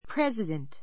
prézədənt